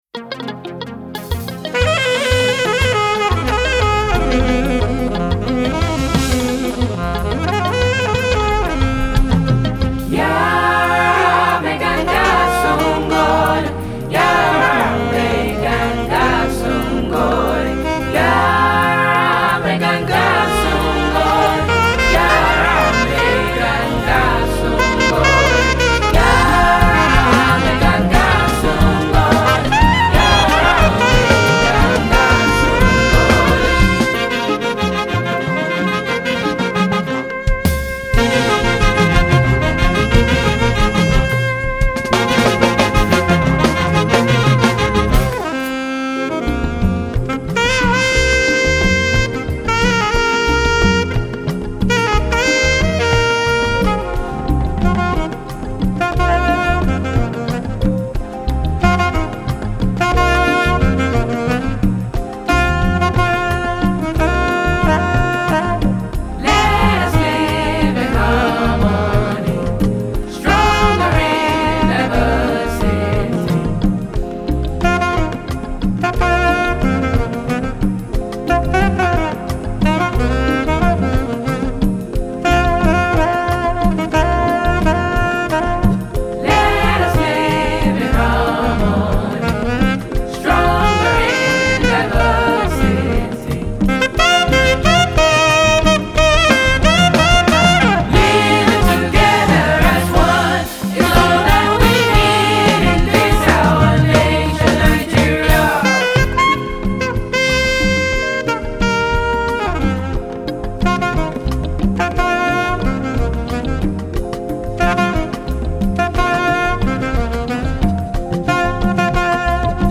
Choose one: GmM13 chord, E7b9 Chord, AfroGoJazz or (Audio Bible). AfroGoJazz